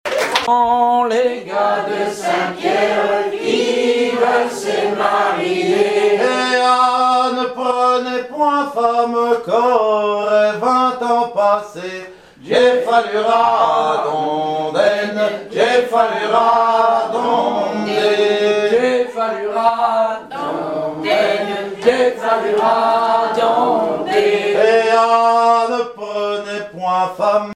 chanté pour le club des anciens à Saint-Pierre-et-Miquelon
Pièce musicale inédite